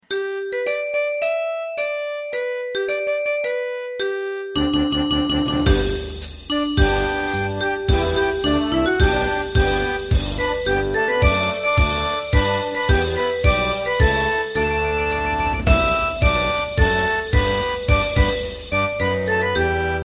大正琴の「楽譜、練習用の音」データのセットをダウンロードで『すぐに』お届け！